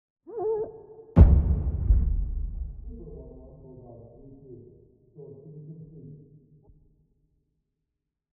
Commotion24.ogg